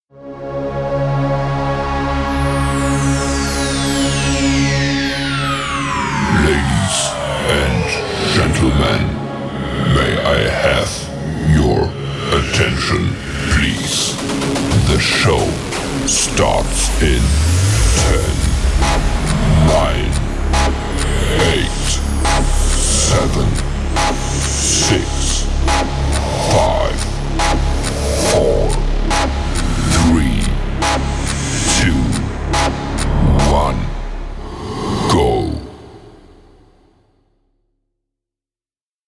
VST для вокодера как на радио
Как получить подобный рыхлый вокал?
Вложения audeption_countdown_intro_ten_to_zero_the_show_starts_005.mp3 audeption_countdown_intro_ten_to_zero_the_show_starts_005.mp3 989,4 KB · Просмотры: 416